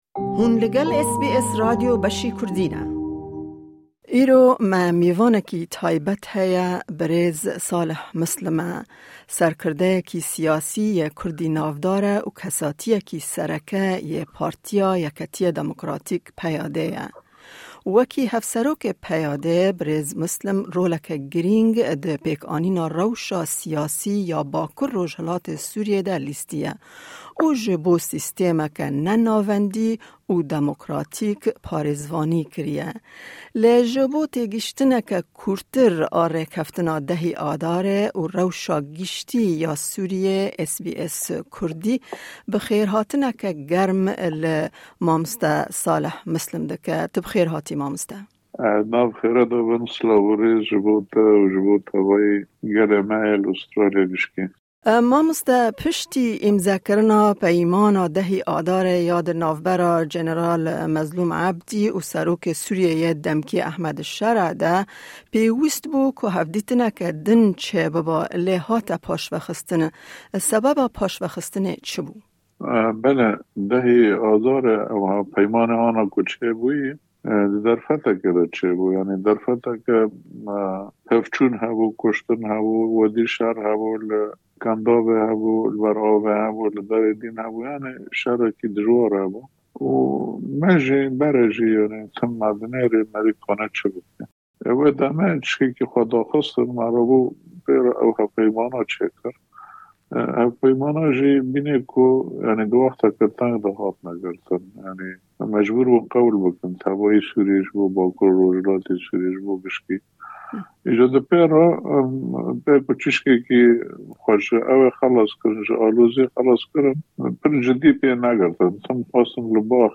Di 10ê Adara 2025an de, serokê demkî yê Sûriyeyê Ehmed Şera û fermandarê HSDê Mezlûm Ebdî peymanek dîrokî îmze kirin da ku HSDê tevlî dezgeha dewleta Sûriyeyê bikin. Em derbarê wê babetê û babetên din bi Hev-Serokê Partiya Yeketiya Demokratîk berêz Salih Mislim re diaxafin.